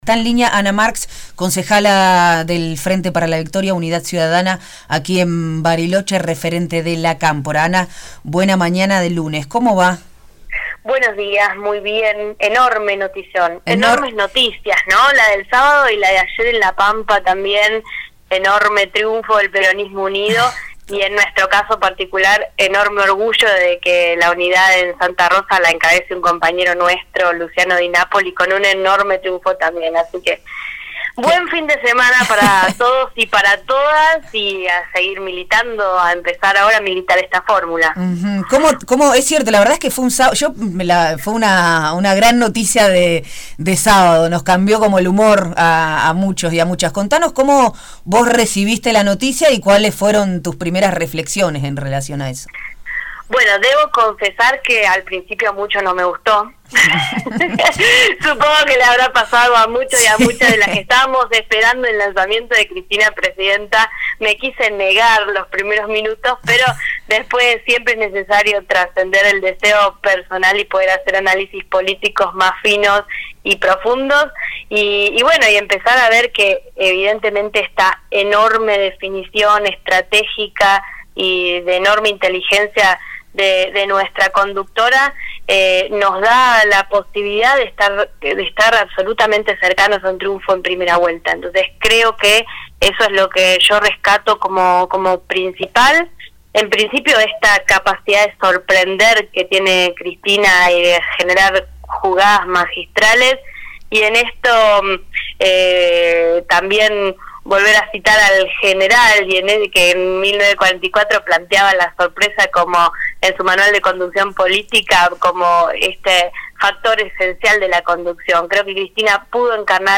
Proyecto Erre entrevistó a representantes del peronismo de Bariloche y Río Negro para conocer sus miradas respecto a una nueva y sorpresiva jugada política de CFK: